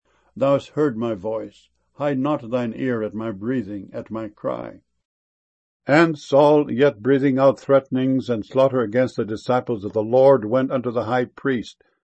breathing.mp3